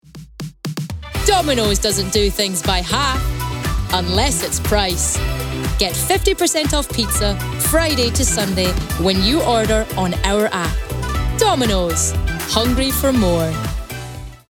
Scottish
Female
Friendly
Warm